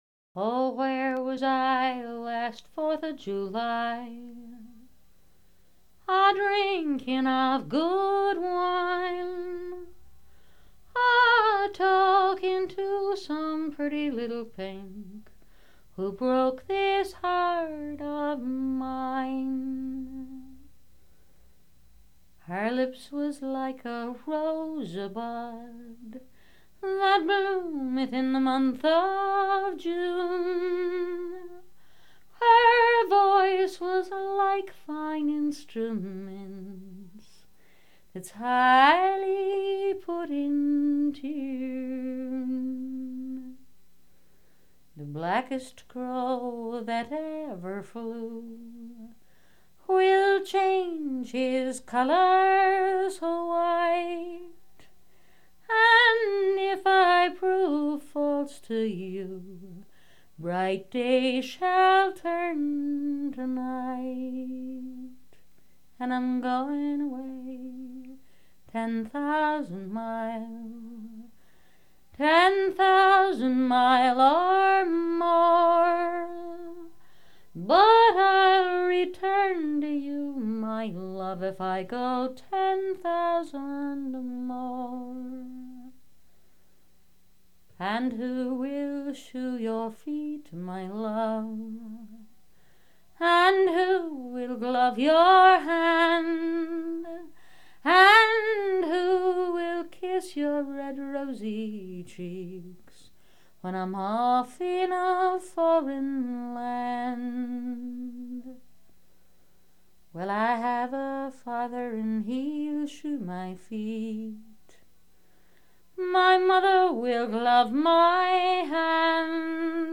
To begin, here’s a traditional song, performed by Rosalie Sorrels.